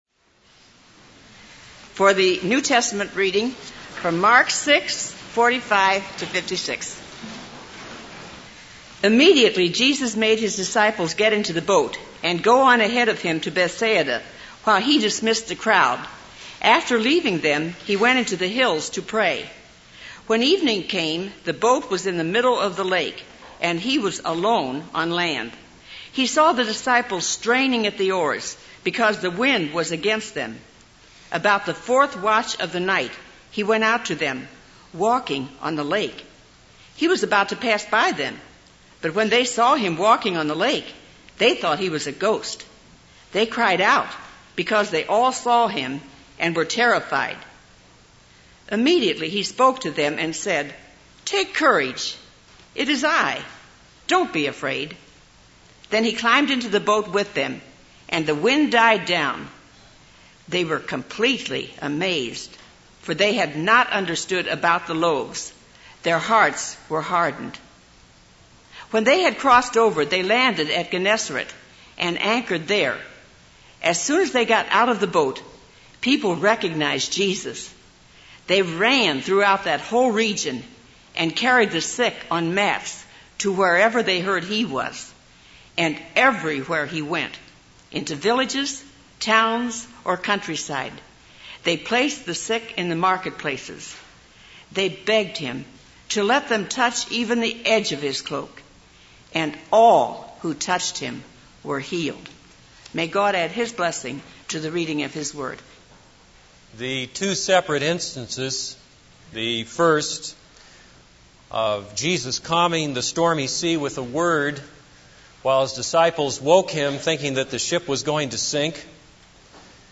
This is a sermon on Mark 6:45-56.